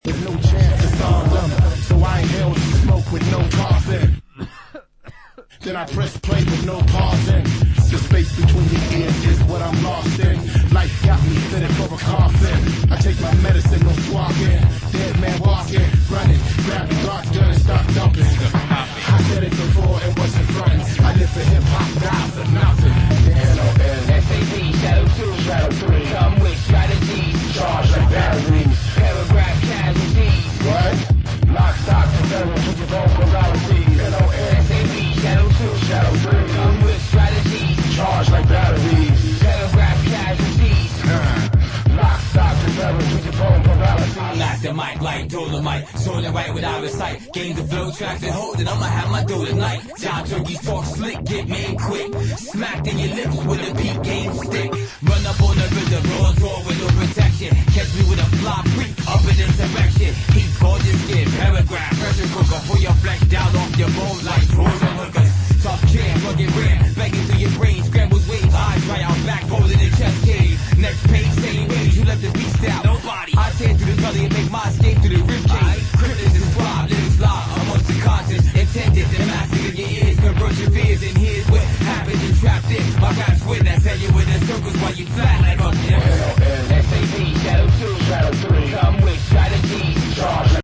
Electro Electronix House